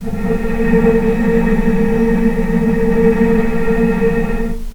healing-soundscapes/Sound Banks/HSS_OP_Pack/Strings/cello/ord/vc-A#3-pp.AIF at ae2f2fe41e2fc4dd57af0702df0fa403f34382e7
vc-A#3-pp.AIF